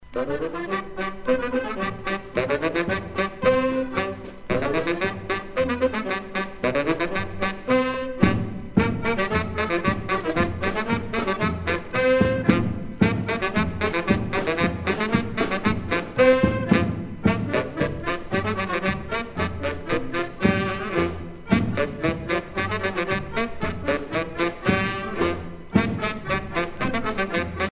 danse : branle